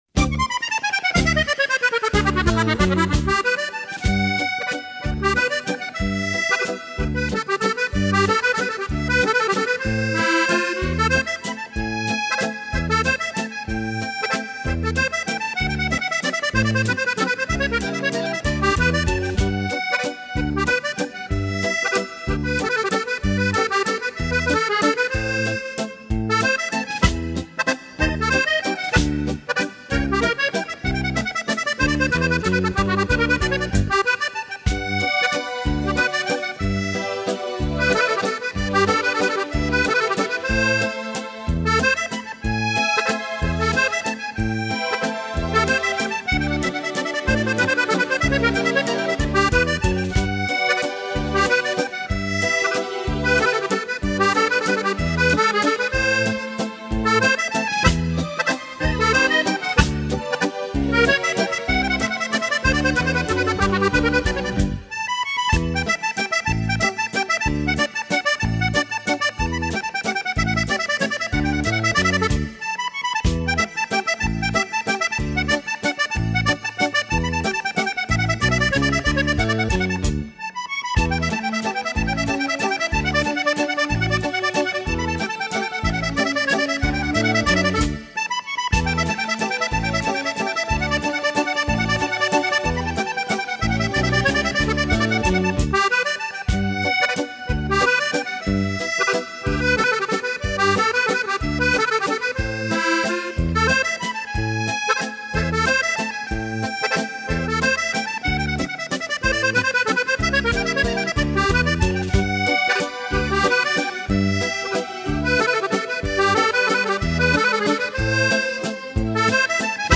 VALZER musette